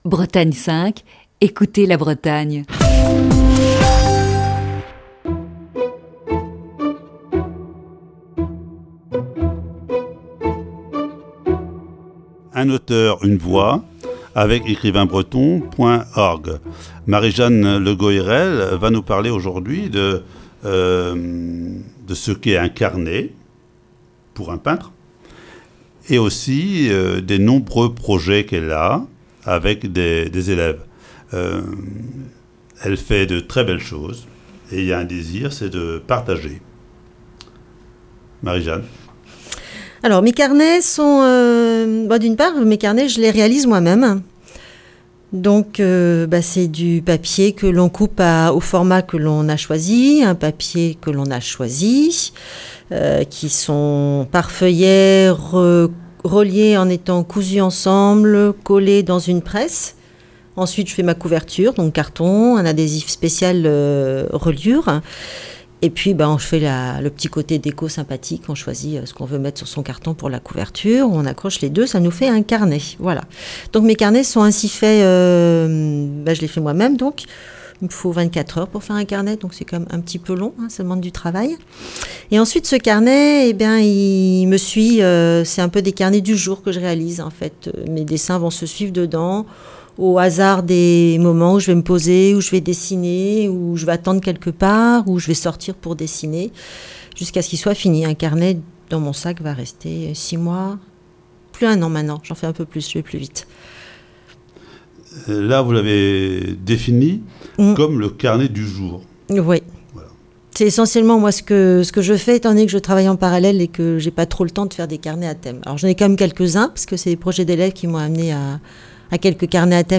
Ce jeudi, voici la quatrième partie de cet entretien.